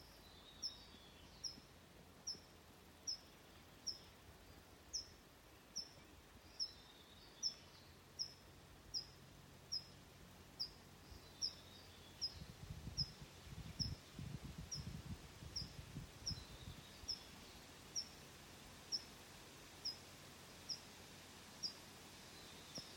Birds -> Pipits ->
Tree Pipit, Anthus trivialis
Administratīvā teritorijaValkas novads